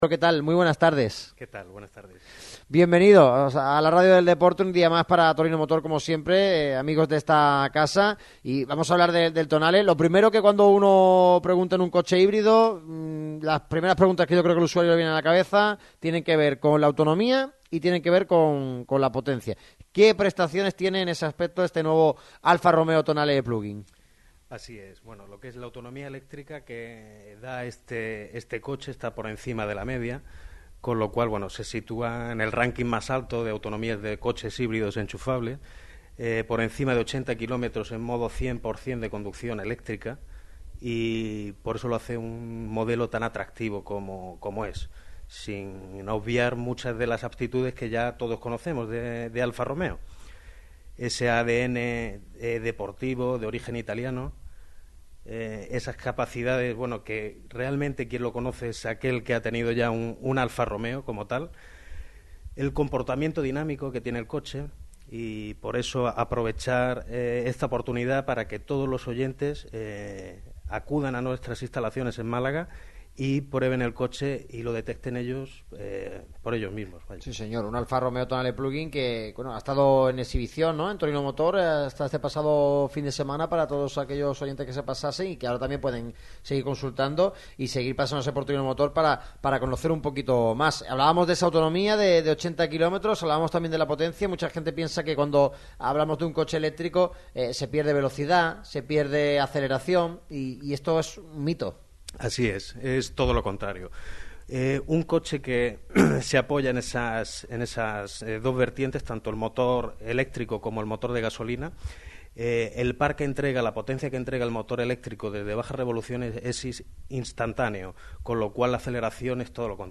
Radio MARCA Málaga se desplaza hasta las instalaciones de Torino Motor, concesionario oficial de Alfa Romeo en Málaga, con sede en la calle Cuevas Bajas, 11.